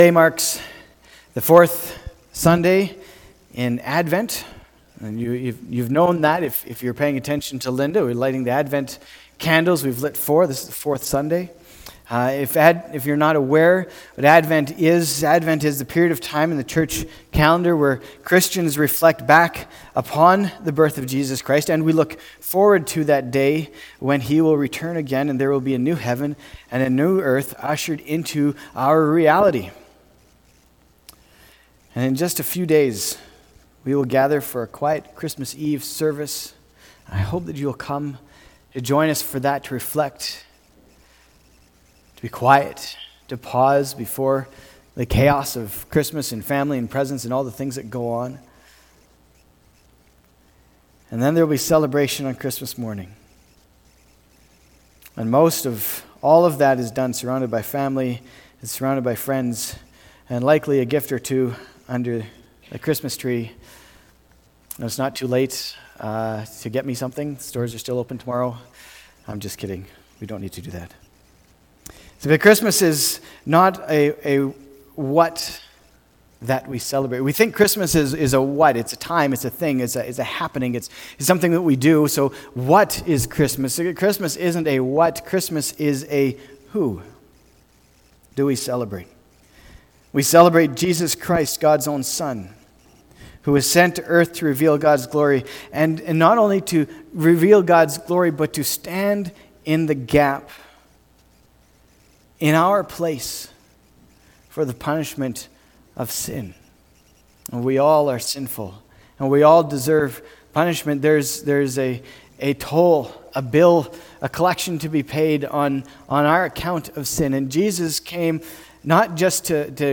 december-22-2019-sermon.mp3